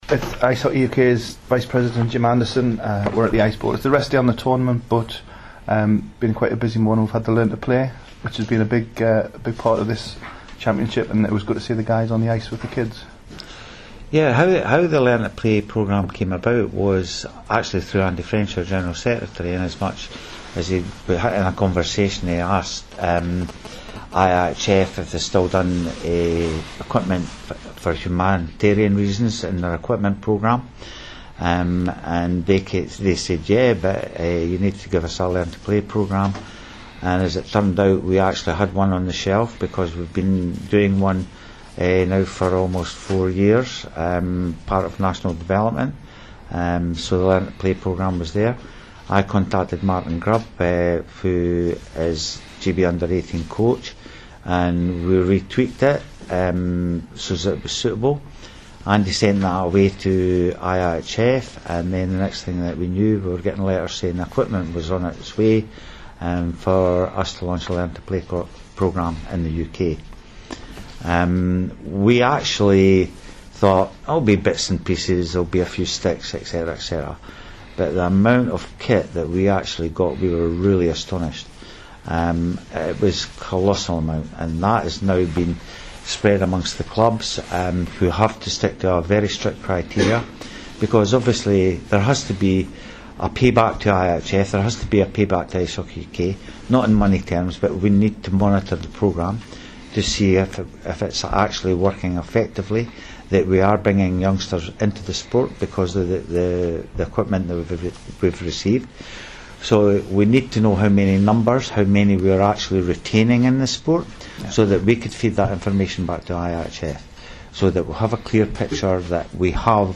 at the launch of the federation's Learn to Play programme at Dumfries